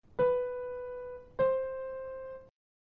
المسافات الصوتية الثنائية
استمع إلى المسافات الثنائية التالية ثم حدد إن كانت صاعدة أو نازلة